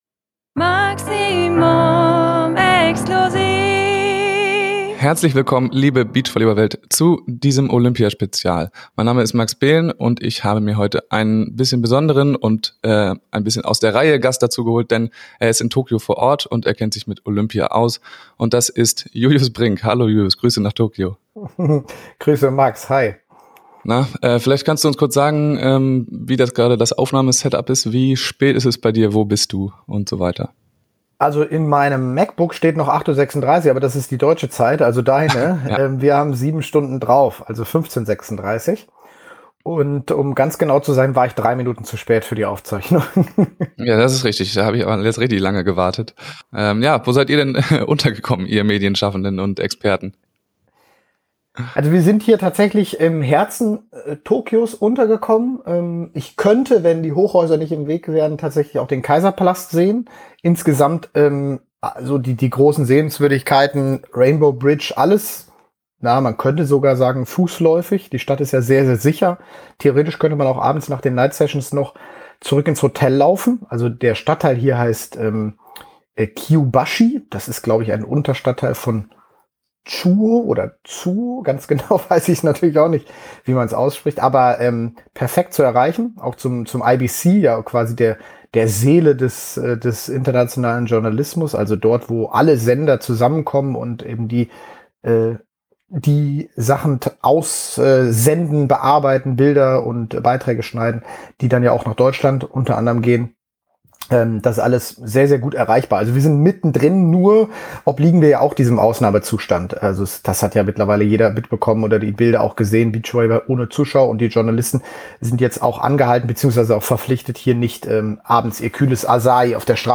Dieser Punkt auf der Bucket List ist nun auch abgehakt, denn Julius Brink meldet sich direkt aus Tokio.
Insights direkt aus Tokio! In dieser Episode zu Gast: Julius Brink Gutes Tun mit dem Kauf einer Mantahari/Maximum Cap: Ihr helft mir mit dem Kauf wirklich sehr und ebenso den Mantas.